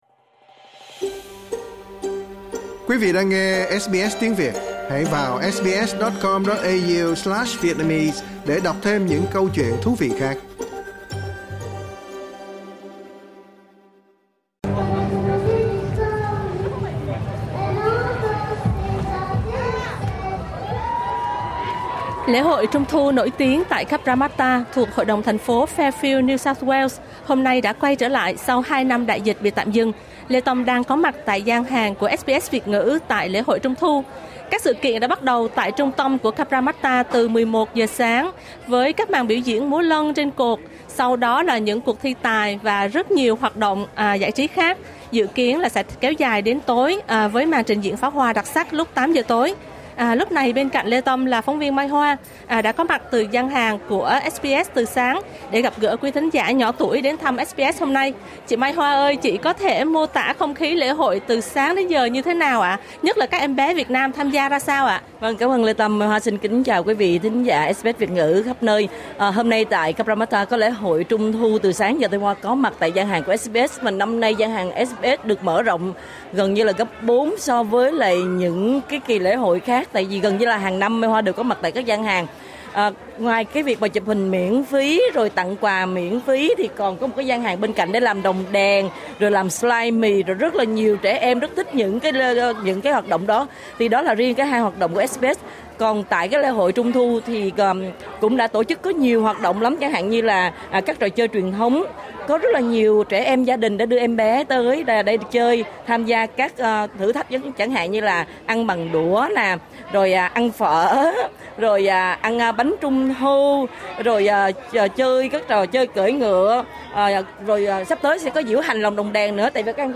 phần tường thuật đặc biệt